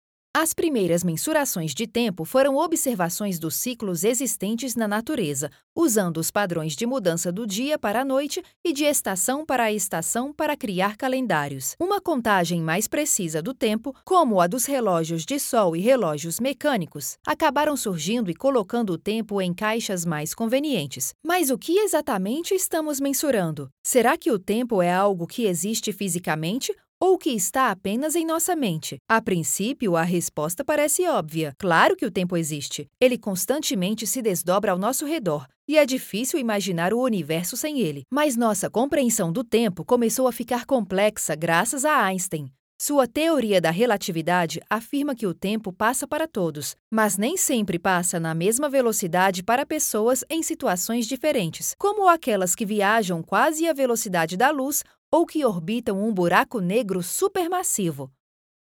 E-learning
My vocal range spans ages 13 to 40.
HighMezzo-Soprano